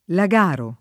[ la g# ro ]